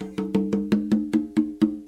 Percs
DETOXPerc.wav